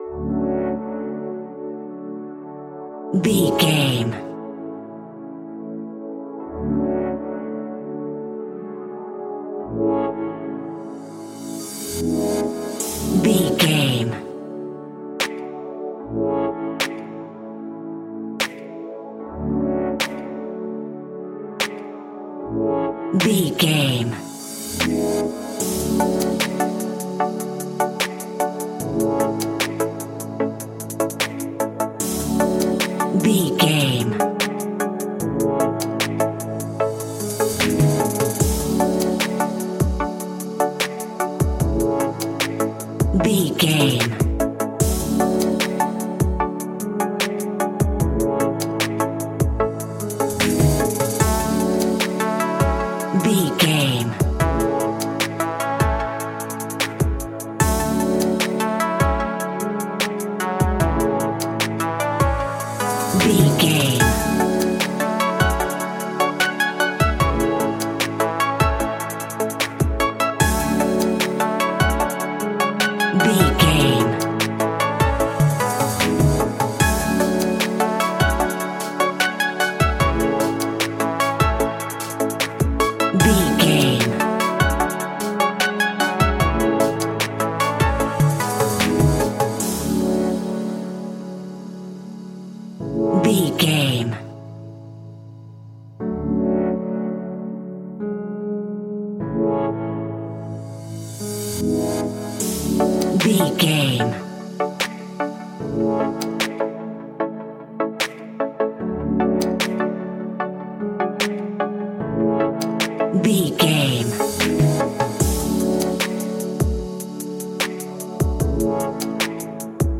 Ionian/Major
hip hop
hip hop production music
chilled
laid back
groove
hip hop drums
hip hop synths
piano
hip hop pads